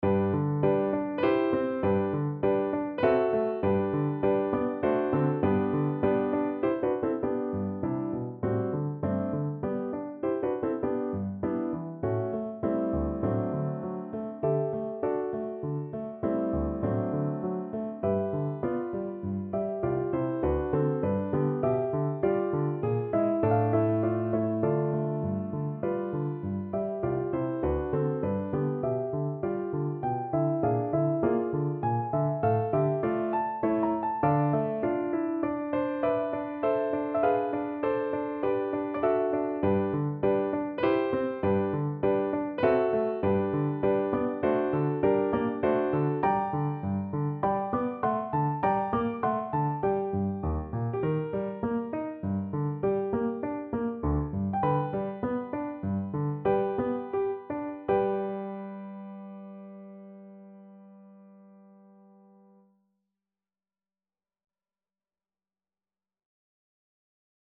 No parts available for this pieces as it is for solo piano.
G major (Sounding Pitch) (View more G major Music for Piano )
~ = 100 Allegretto grazioso (quasi Andantino) (View more music marked Andantino)
3/4 (View more 3/4 Music)
Instrument:
Piano  (View more Advanced Piano Music)
Classical (View more Classical Piano Music)